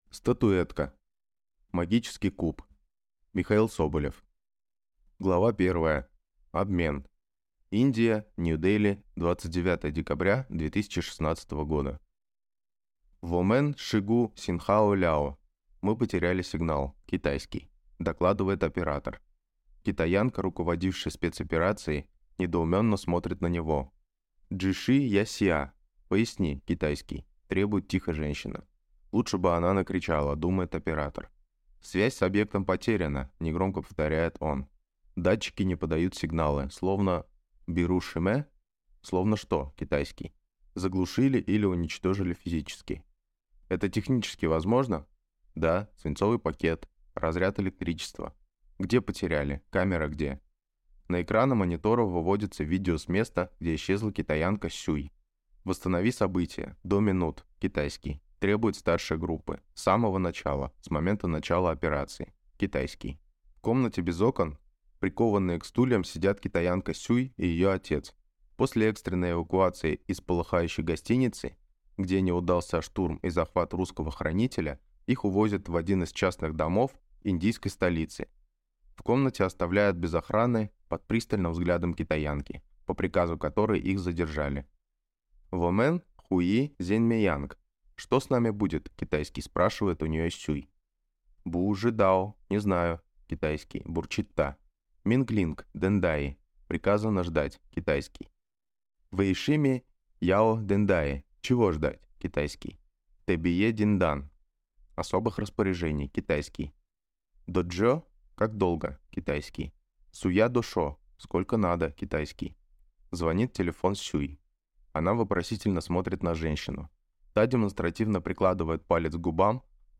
Аудиокнига Статуэтка. Магический куб | Библиотека аудиокниг